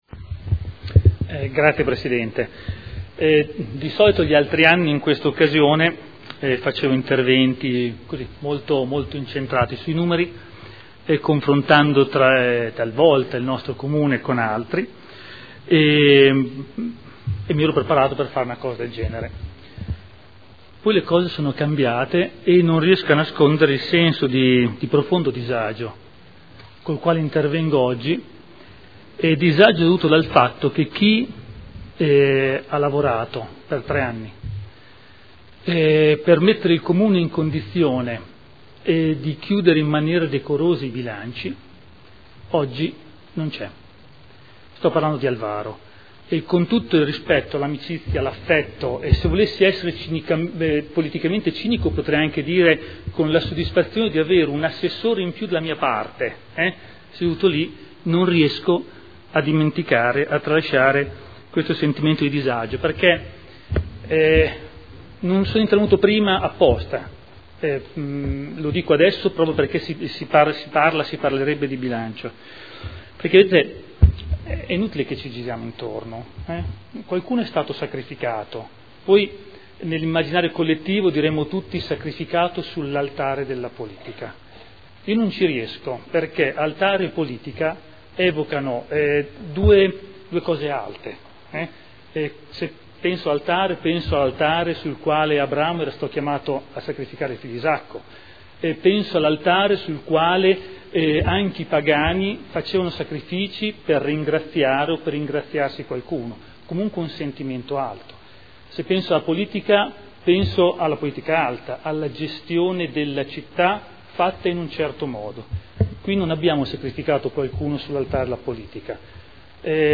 Gian Domenico Glorioso — Sito Audio Consiglio Comunale